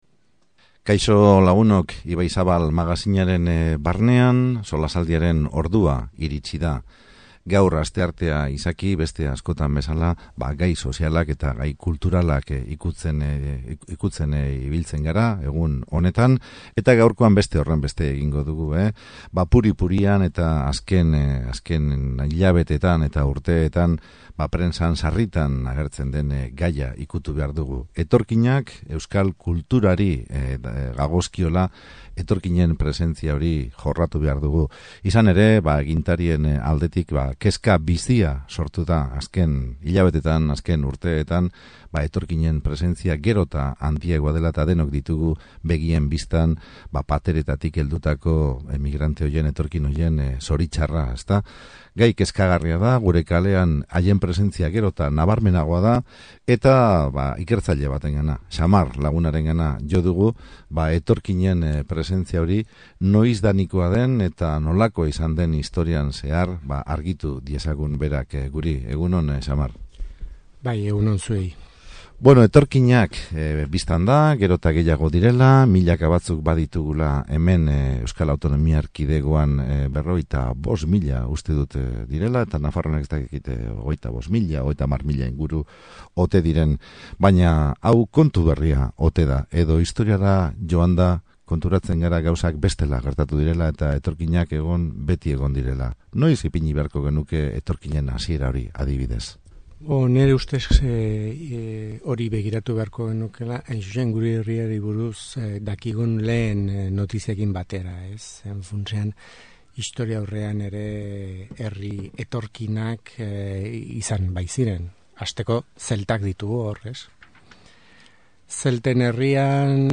SOLASALDIA: Etorkinak Euskal Herriko historian zehar